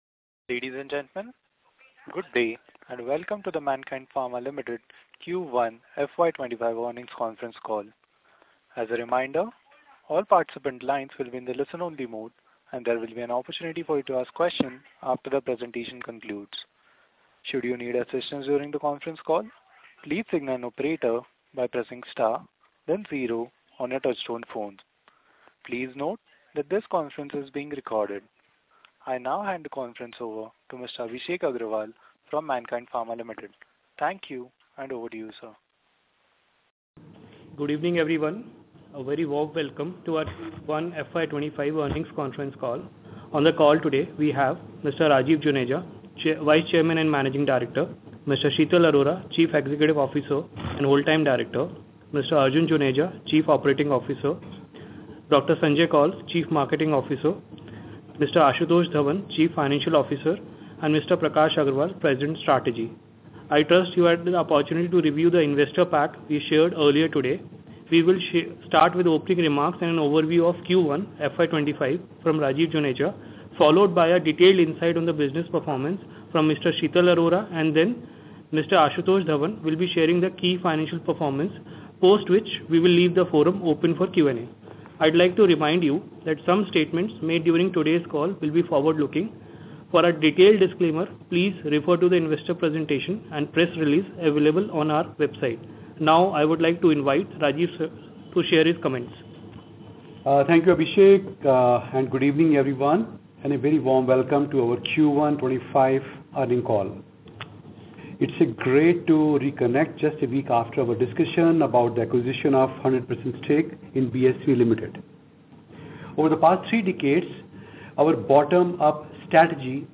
Q2FY25 Earnings Conference Call Recording